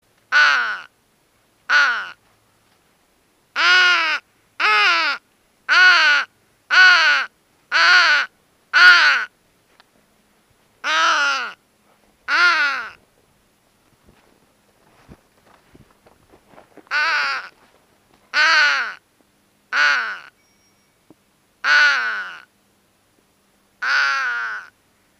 Звуки вороны
Звук подраненной вороны в манке для охоты